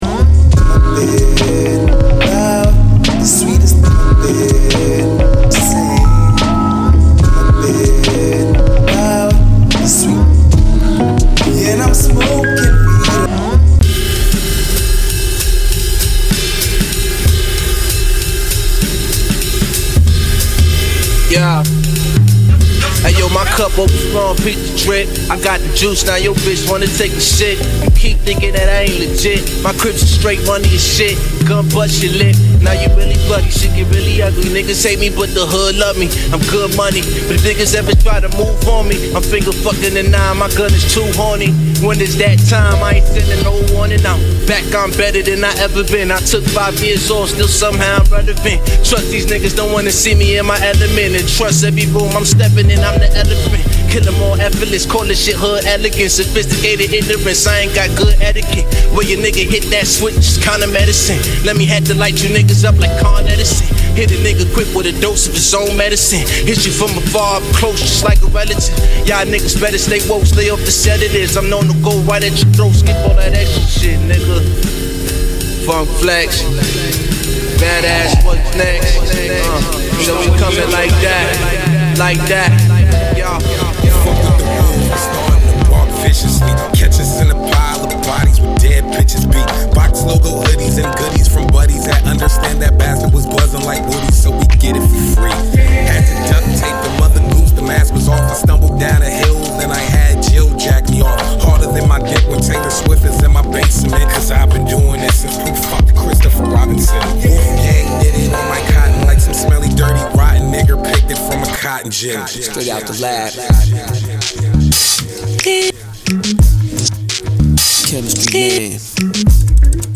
独特の浮遊感と美しさ、そして絶妙なループ感覚は唯一無二。